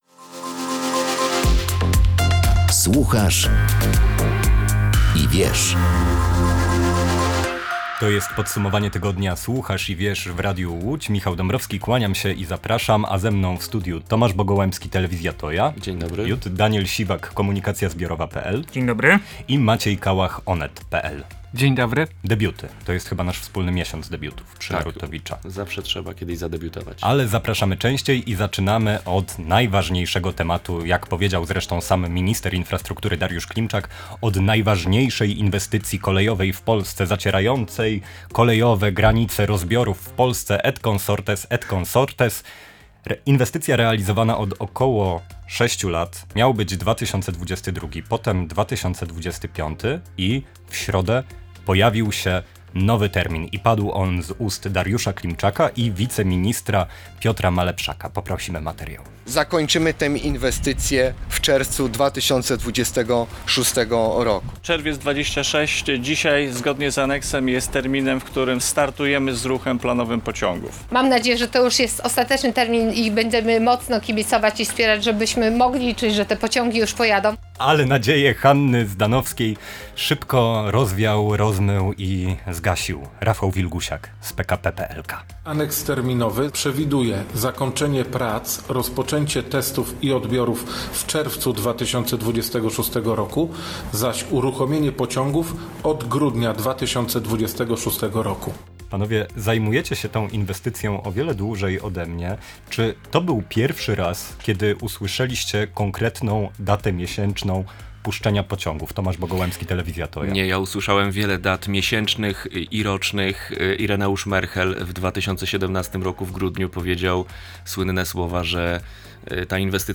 W sobotę (18 maja) o godz. 6 podsumowaliśmy tydzień w dziennikarskim gronie.